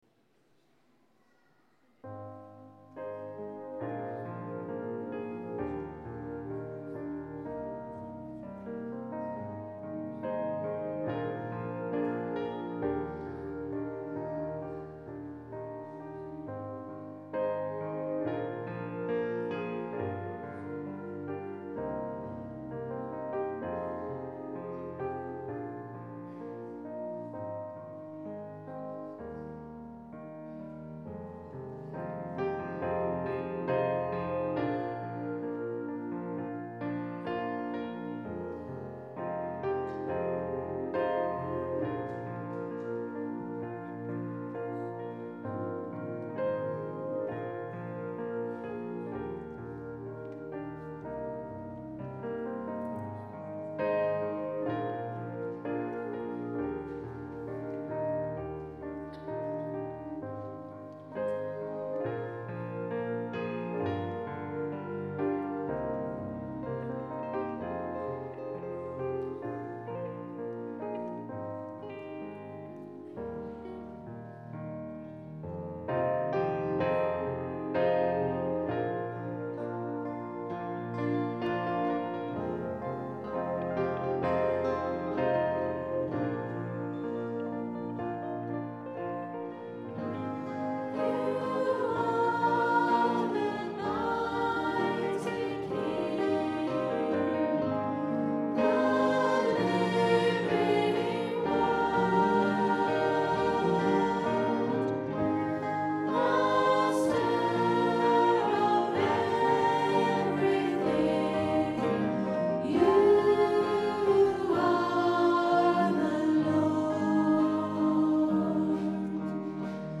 Recorded on a Zoom H4 digital stereo recorder at 10am Mass Sunday 18th July 2010.